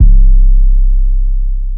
808 Metro Vault.wav